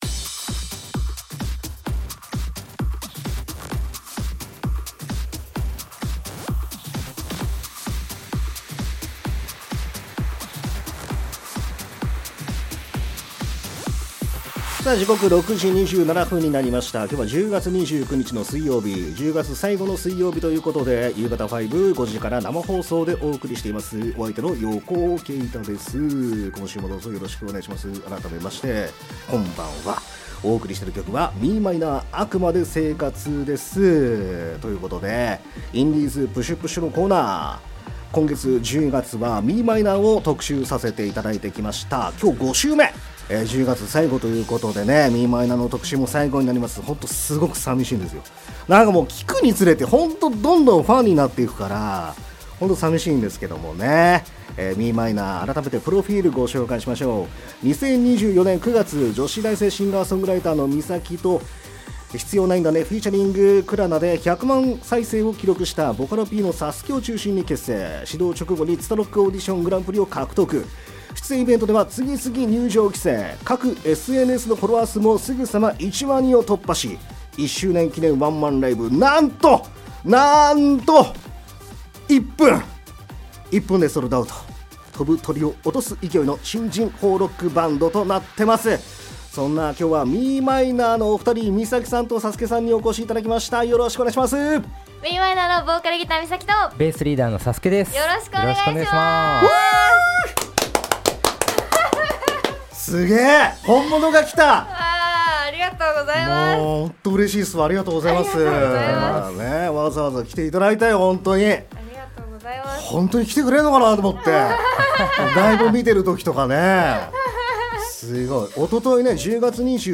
※アーカイブでは楽曲カットしていますが、弾き語りは入っています。